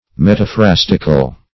Search Result for " metaphrastical" : The Collaborative International Dictionary of English v.0.48: Metaphrastic \Met`a*phras"tic\, Metaphrastical \Met`a*phras"tic*al\, a. [Gr.